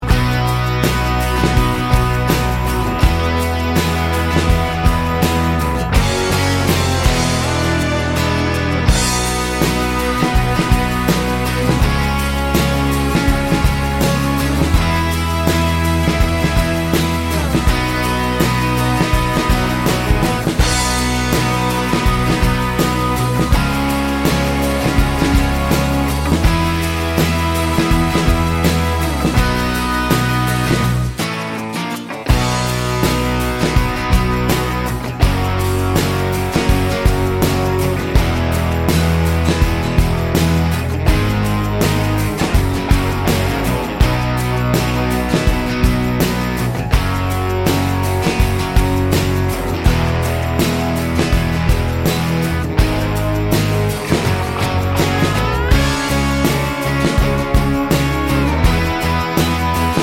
no Backing Vocals Indie / Alternative 4:48 Buy £1.50